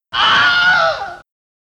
1 murder scream